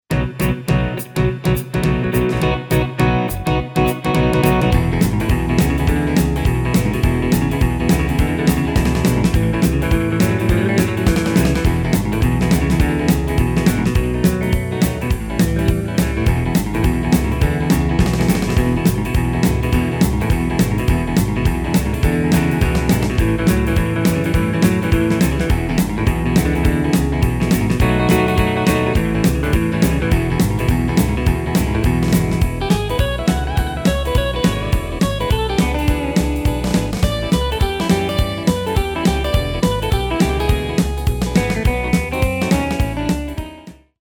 Demo/Koop midifile
Taal uitvoering: Instrumentaal
Genre: Rock & Roll / Boogie / Twist / Rockabilly
Toonsoort: E